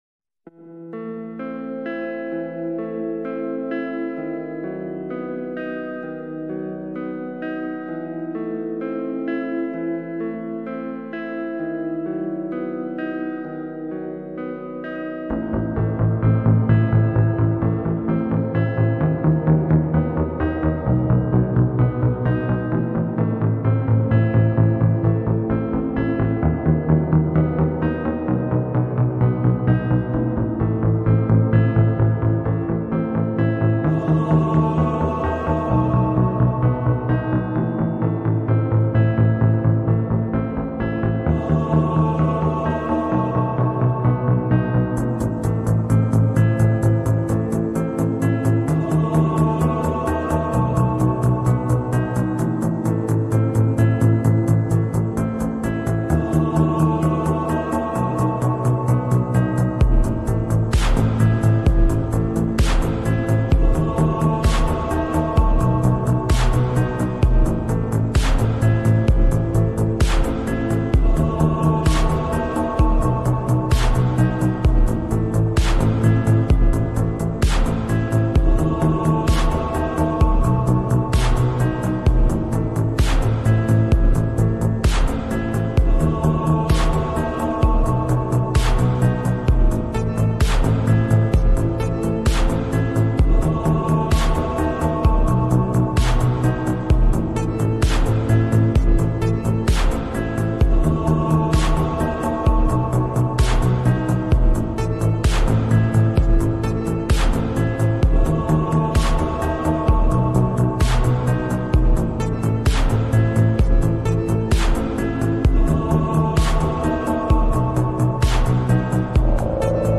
Vegeta speech